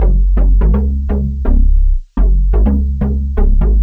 cch_bass_loop_chorus_125_F#m.wav